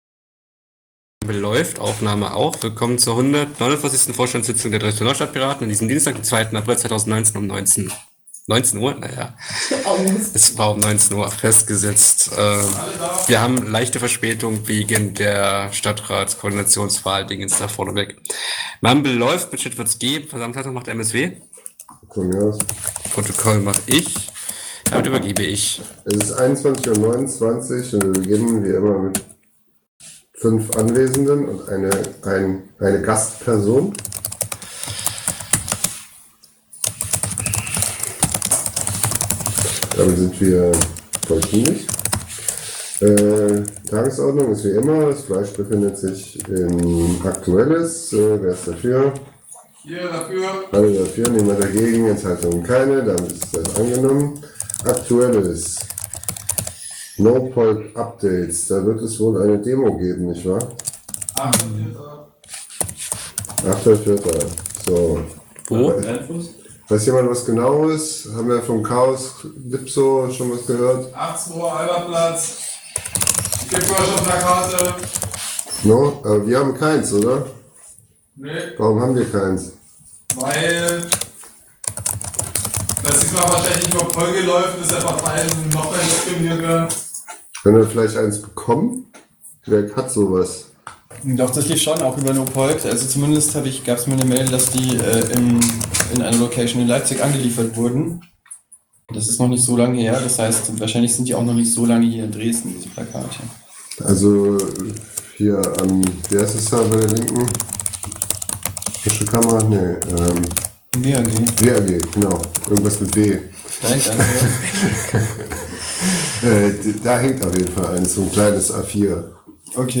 149. Vorstandssitzung der Dresdner Neustadtpiraten